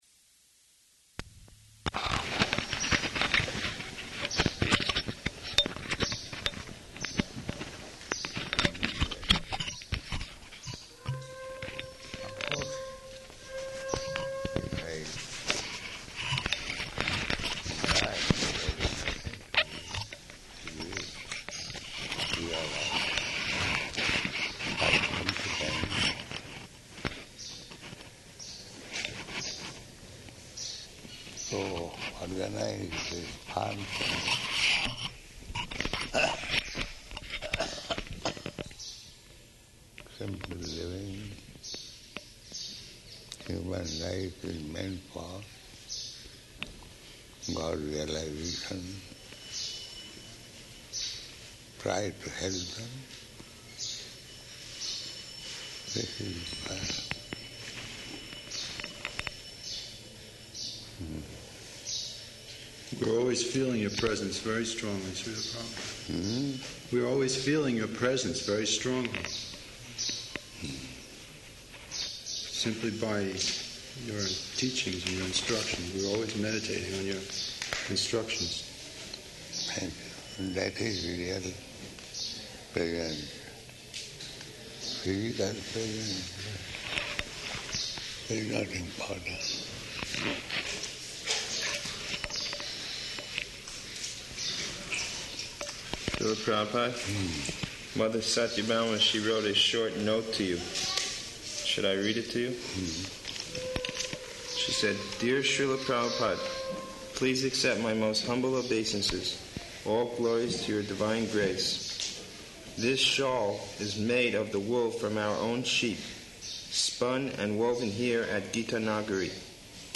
Room Conversation
Room Conversation --:-- --:-- Type: Conversation Dated: October 6th 1977 Location: Vṛndāvana Audio file: 771006R2.VRN.mp3 Prabhupāda: Of course, if I die, there is nothing to be ruined.